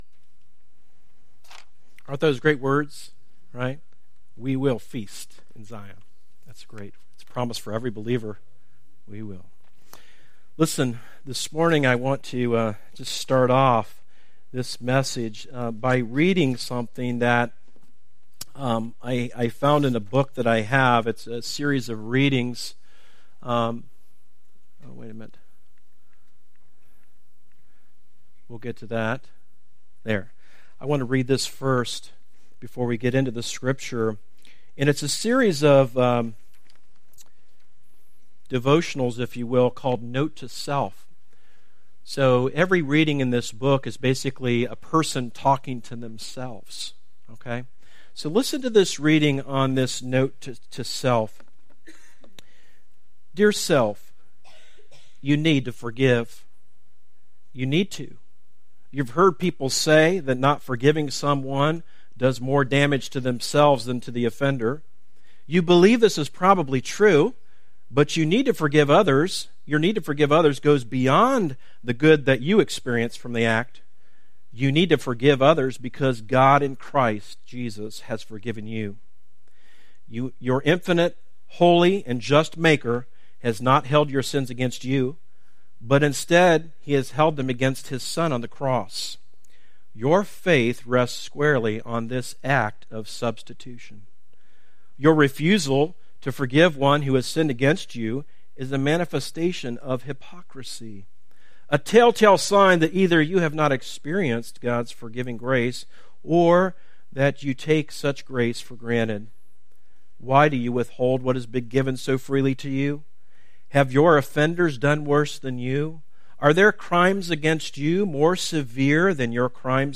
Sermons - series archive - Darby Creek Church - Galloway, OH